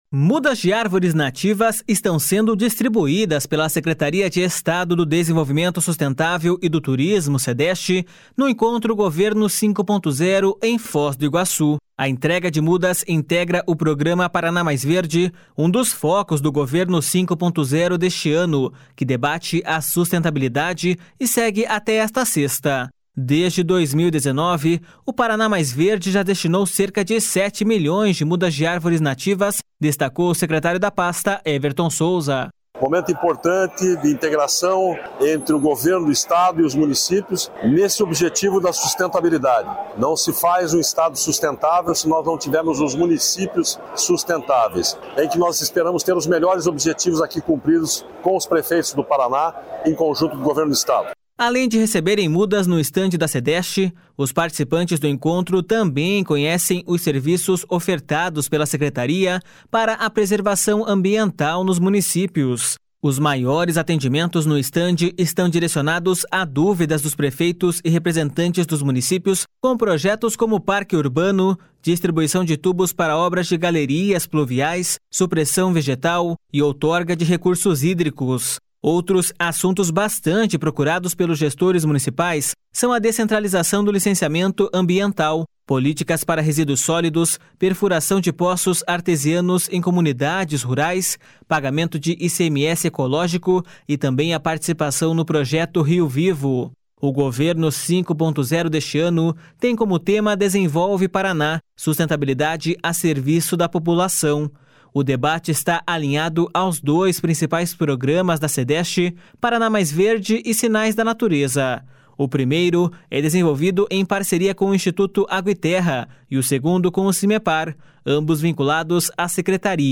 Desde 2019, o Paraná Mais Verde já destinou cerca de sete milhões de mudas de árvores nativas, destacou o secretário da pasta, Everton Souza.// SONORA EVERTON SOUZA.//